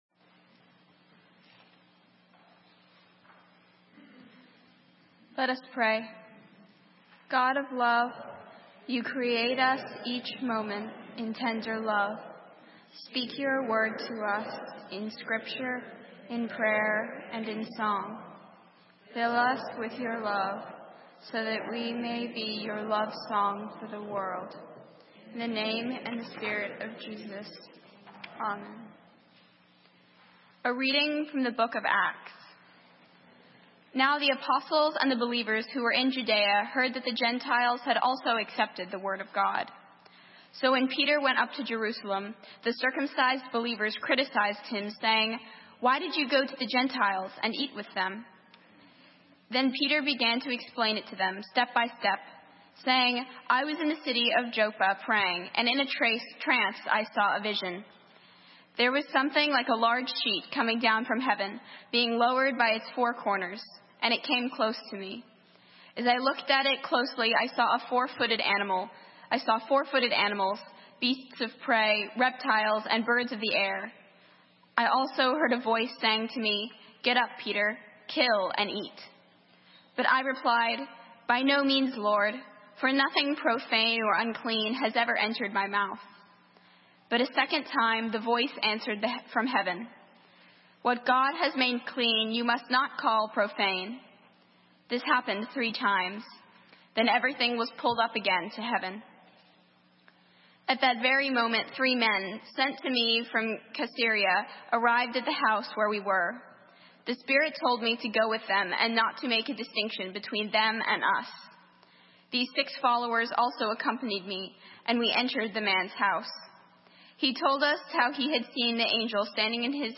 Today’s Service consisted of scriptures and a Hymn Sing; there was no sermon.